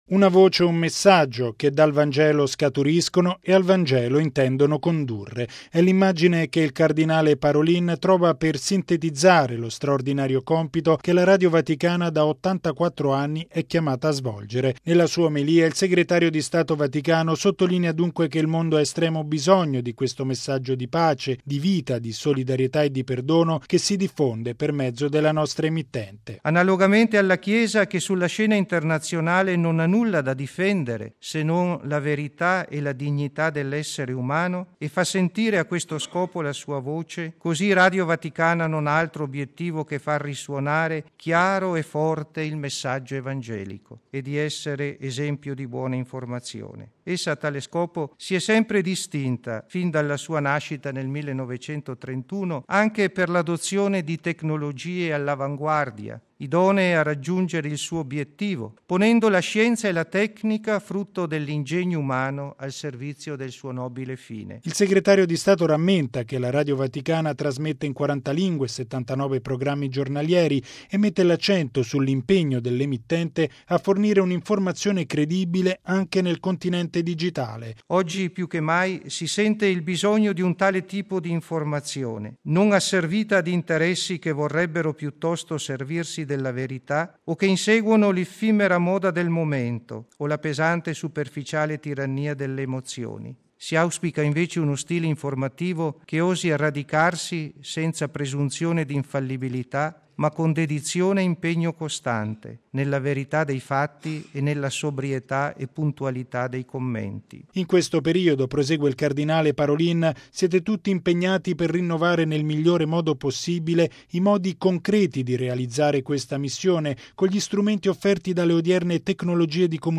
Nella sua omelia, il segretario di Stato vaticano sottolinea dunque che il mondo ha “estremo bisogno” di questo messaggio “di pace, di vita, di solidarietà e di perdono che si diffonde” per mezzo della nostra emittente.